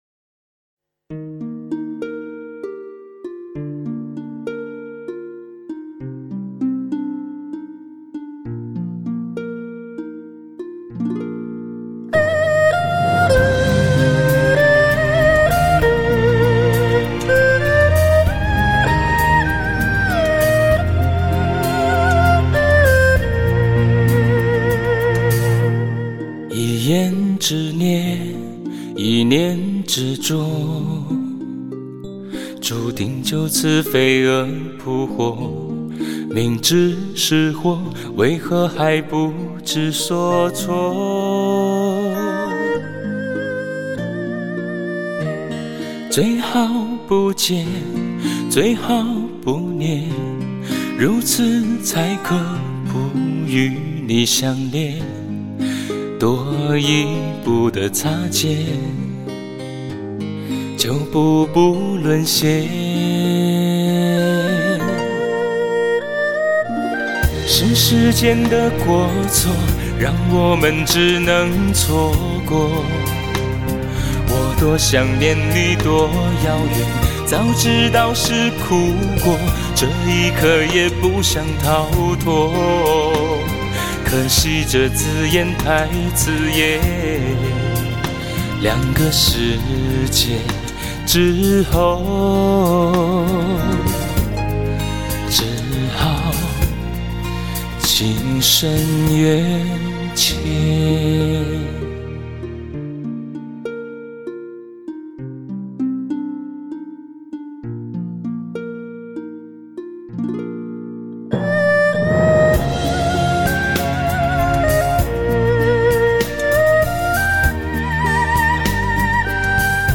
凝聚“非常发烧”的清新风格